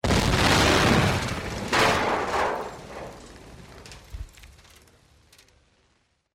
audio clip portraying a large blast with debris from Discovery Education™ streaming.
mod5top3_wu_blast.mp3